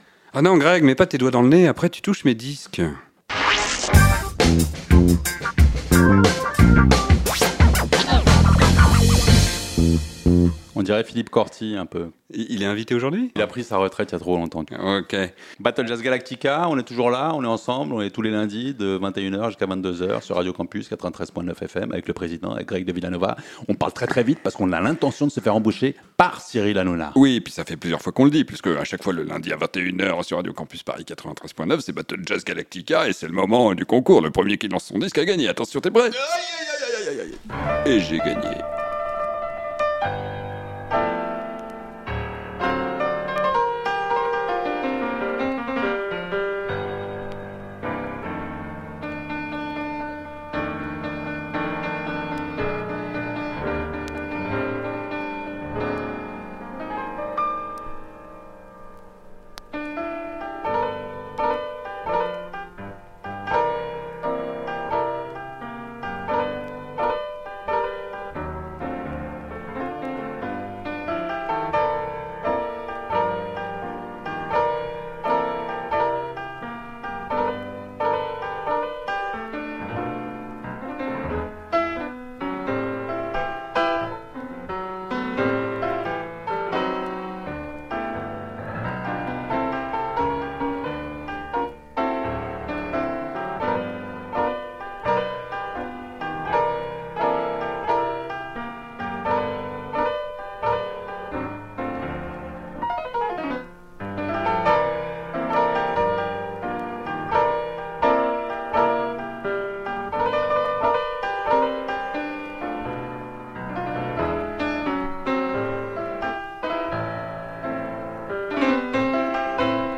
Chacun tire ses cartouches comme si l’histoire du jazz tenait à un solo de plus. La 40ème battle, c’est encore et toujours l’art d’avoir toujours raison — surtout quand on a tort.